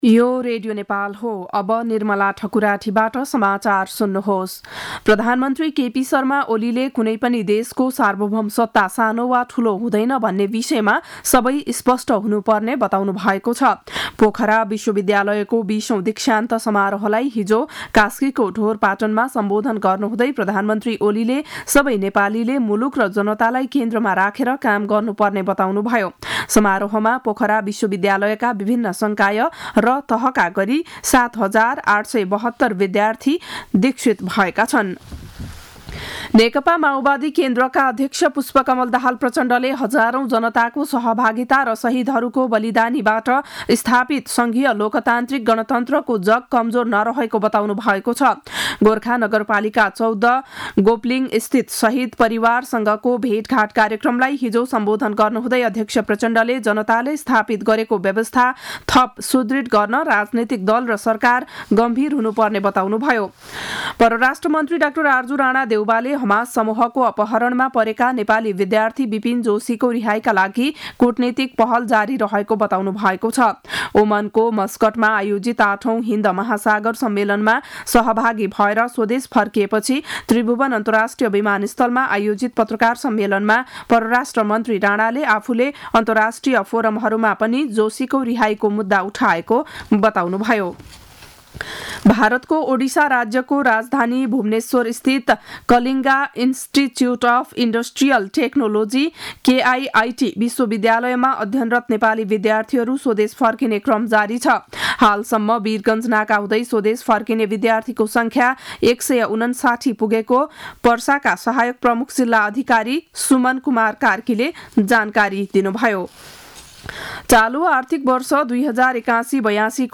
बिहान ११ बजेको नेपाली समाचार : १० फागुन , २०८१
11-am-news-1-8.mp3